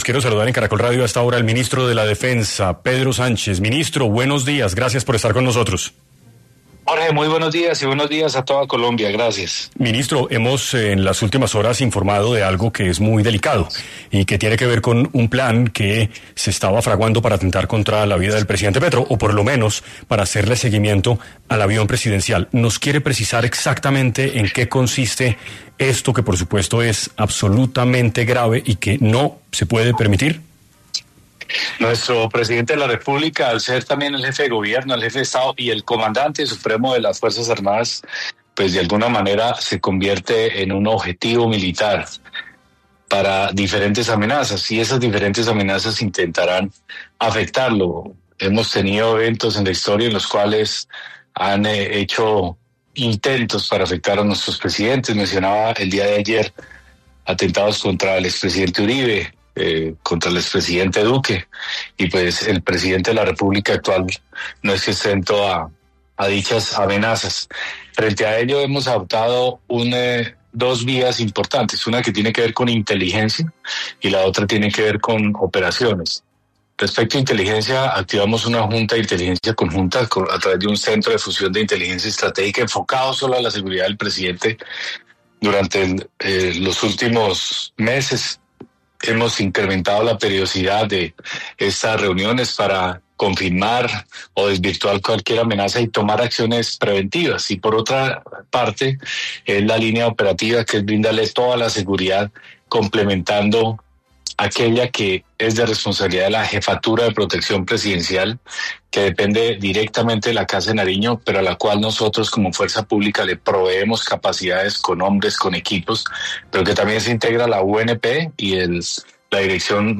Pedro Sánchez, ministro de Defensa, estuvo en 6AM para abordar temas de seguridad que afectan al país, como las presuntas amenazas en contra del presidente Gustavo Petro.